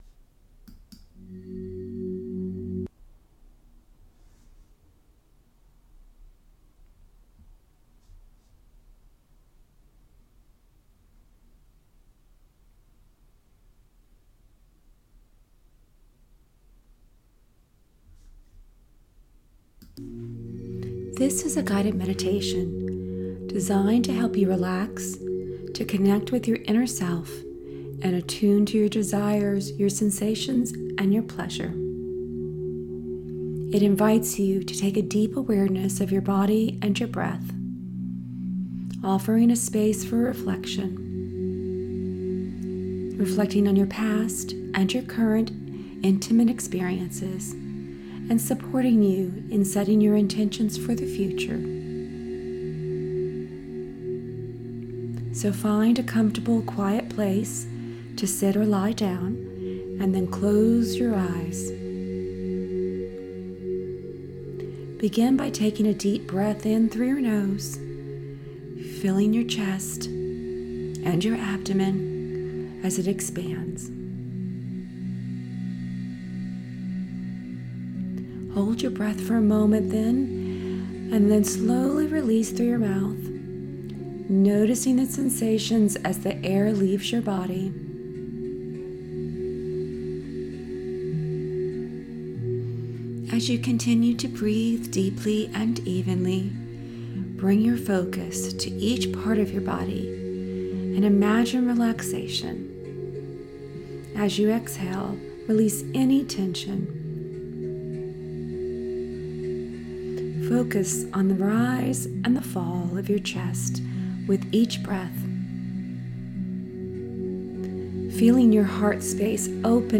Chapter 3 Guided Meditation.